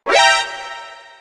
HazardSpawn.ogg